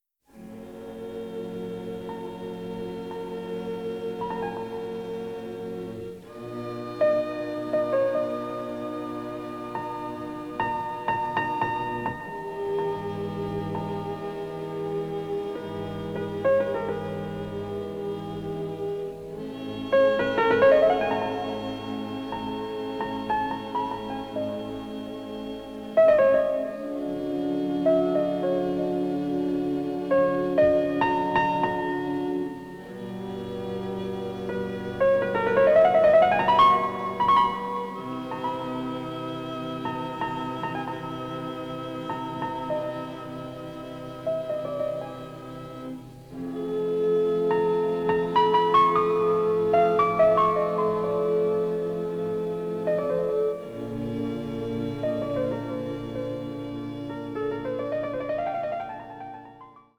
including the tango and Charleston